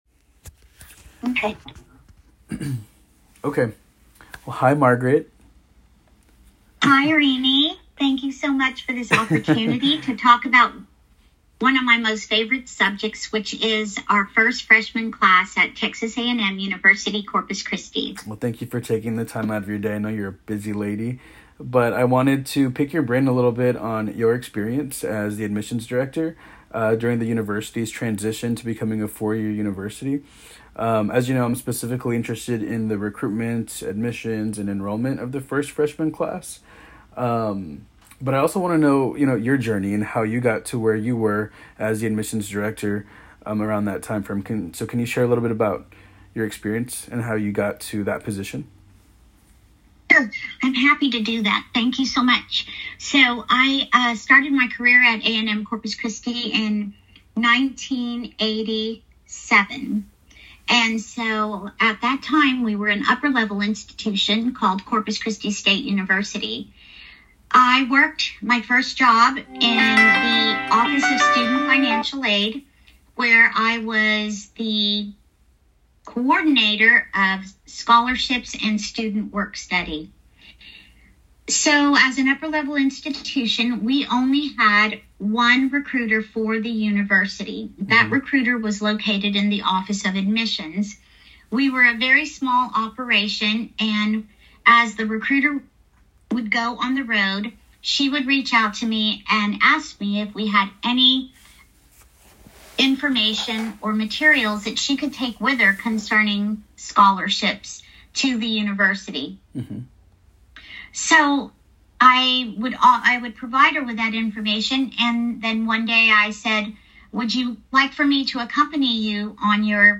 Interview - Part 2